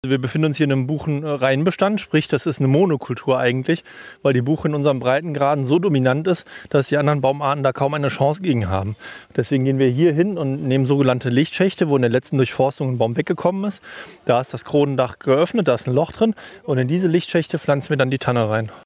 oton-pflanzaktion-buchenreinbestand.mp3